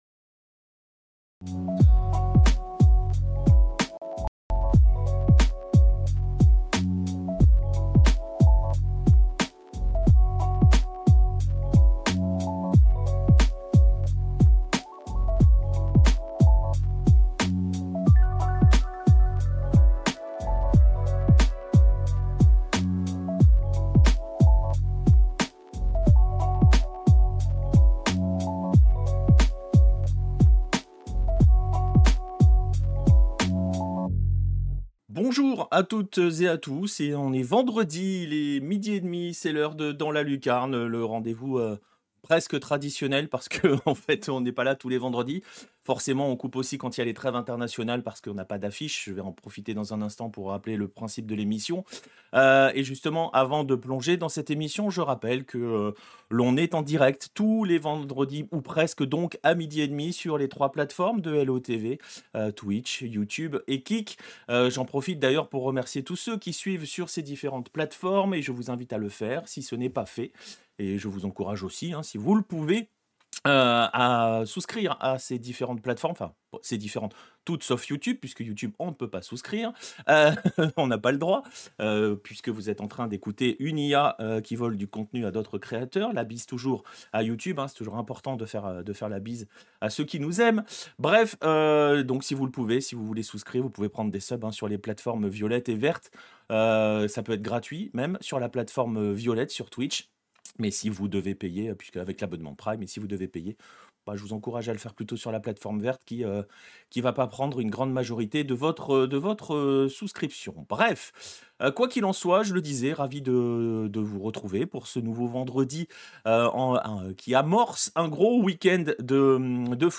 Musique Dreamy Lo-Fi| Chill Vibes